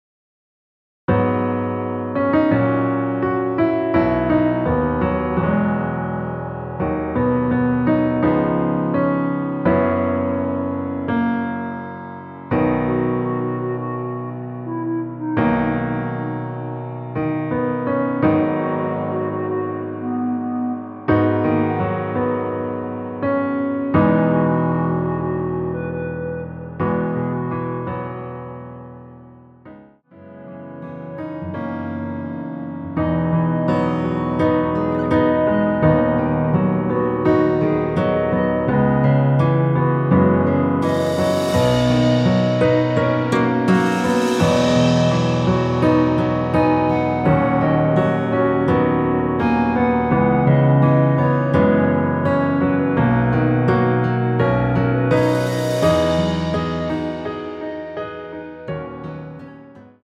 원키 멜로디 포함된 MR입니다.
앞부분30초, 뒷부분30초씩 편집해서 올려 드리고 있습니다.